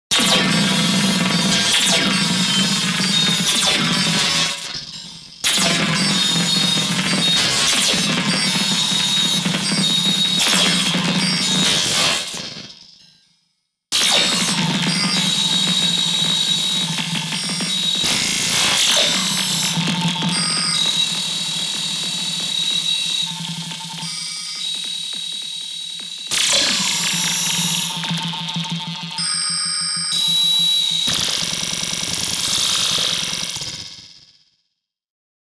Here’s what it would sound like if CY Alloy (4 of them, actually) was executed by electric chair :